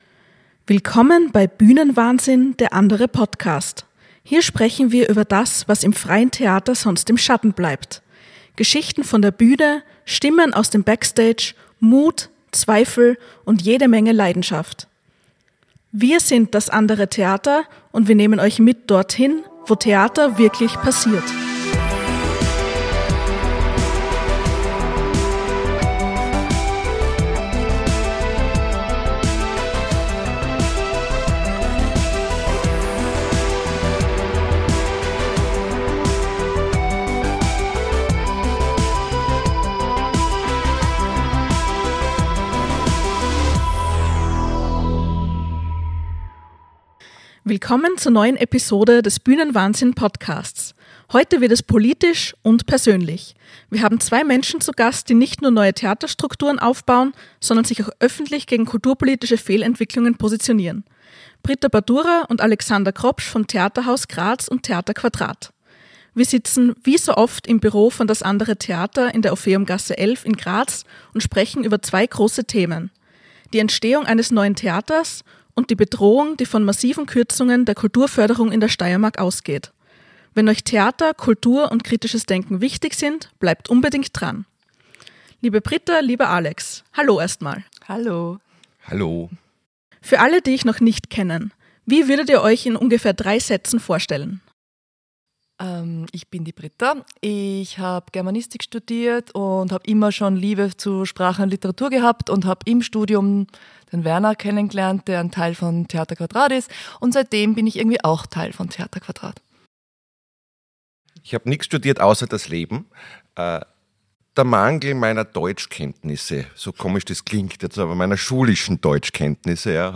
Ein Gespräch über Theaterleidenschaft, Widerstand und kulturpolitische Realität – aufgenommen im Büro von Das andere Theater in Graz.